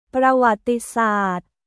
プラワッティサート